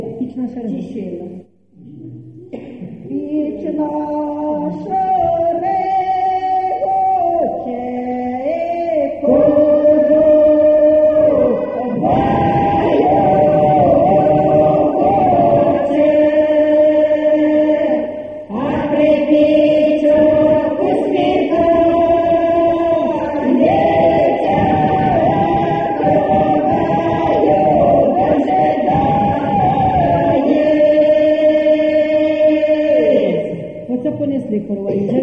ЖанрВесільні
Місце записус. Веселий Поділ, Семенівський район, Полтавська обл., Україна, Полтавщина